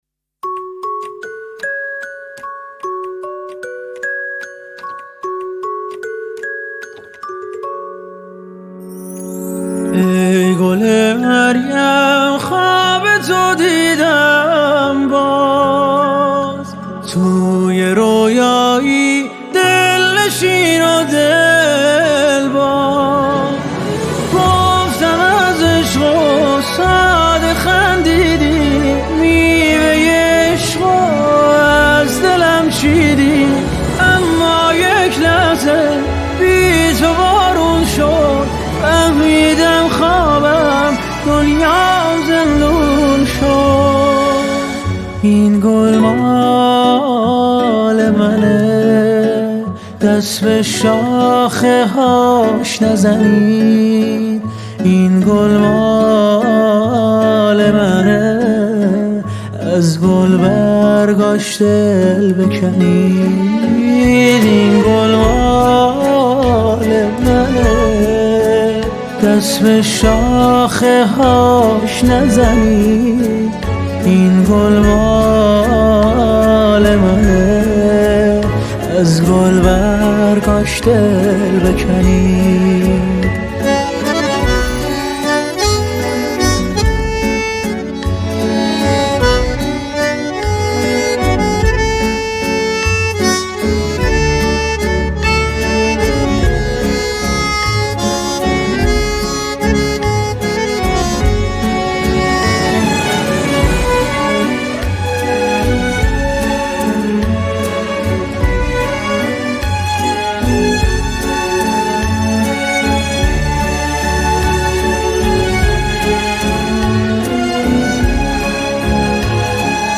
موسیقی ترکی